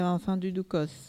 Localisation Bois-de-Céné
Catégorie Locution